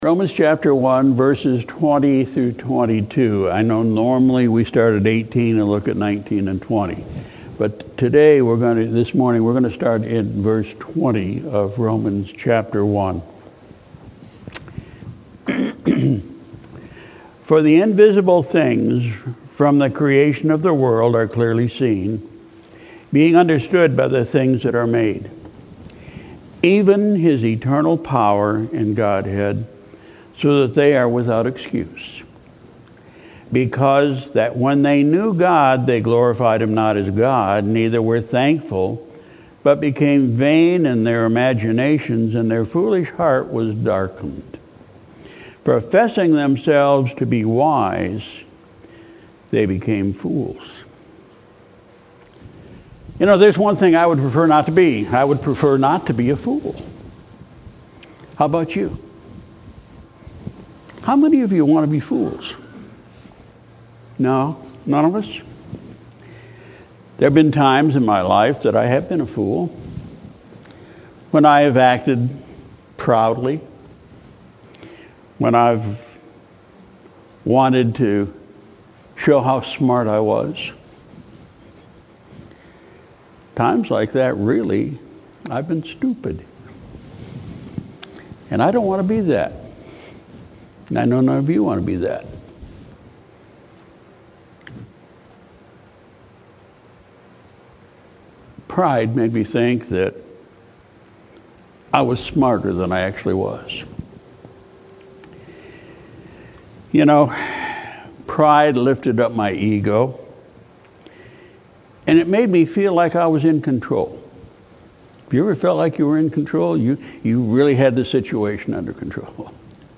Sunday, September 9, 2018 – Morning Service – Coastal Shores Baptist Church